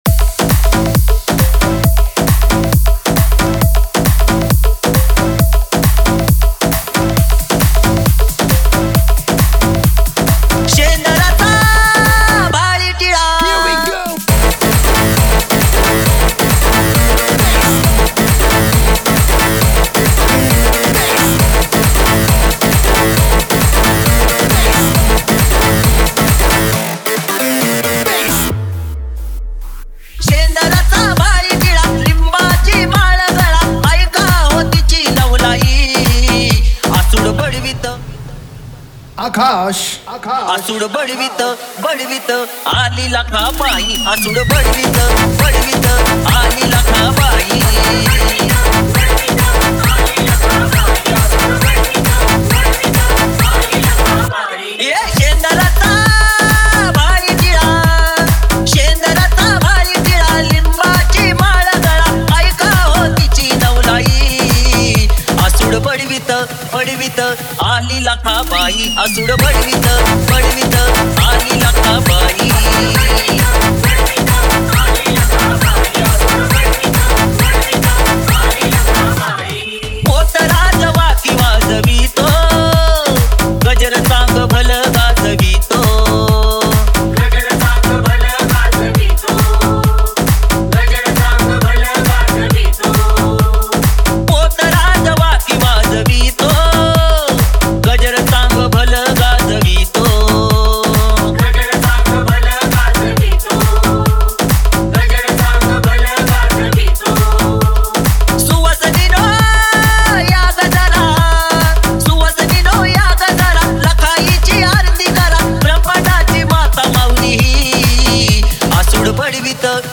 Navratri Dj Remix Song Play Pause Vol + Vol